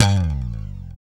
Index of /90_sSampleCDs/Roland LCDP02 Guitar and Bass/BS _Jazz Bass/BS _E.Bass FX